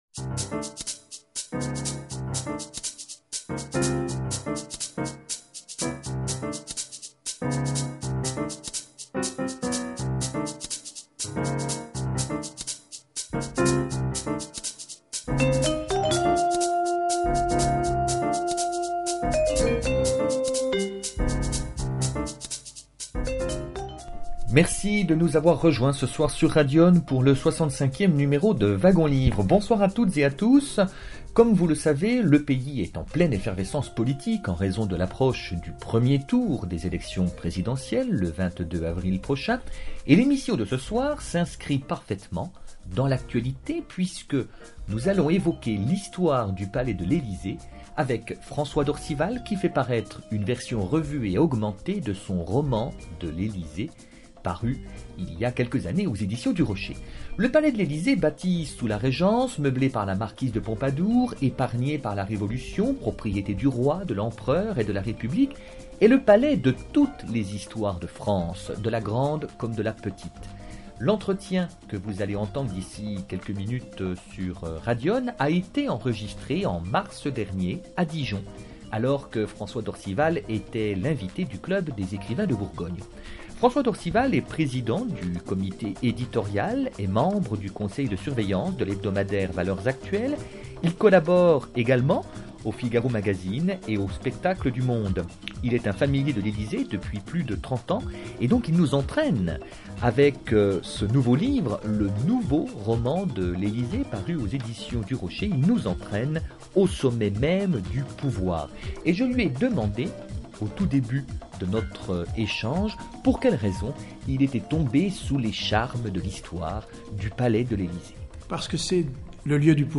Un entretien enregistré à Dijon, en mars 2012, au Grand Hôtel de La Cloche, alors que François D’ORCIVAL était l’invité du Club des Écrivains de Bourgogne.